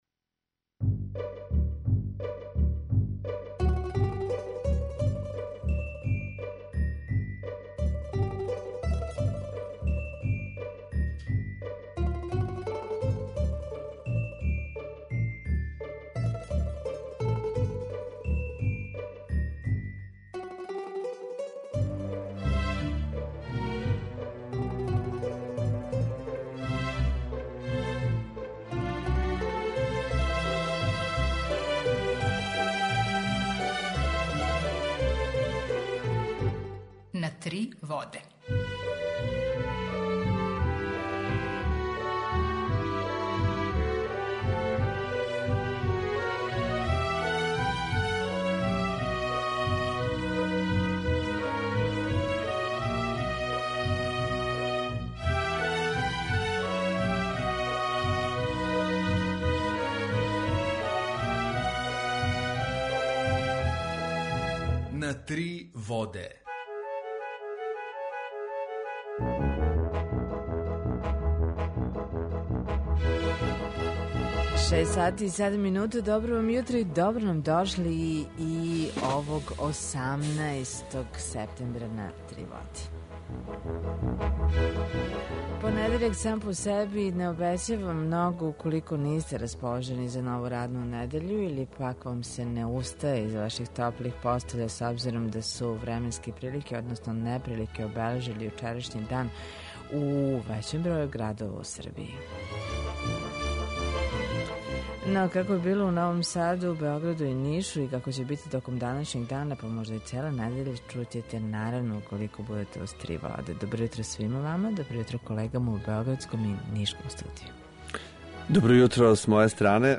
Jутарњи програм заједнички реализују Радио Београд 2, Радио Нови Сад и дописништво Радио Београда из Ниша.
У два сата биће и добре музике, другачије у односу на остале радио-станице.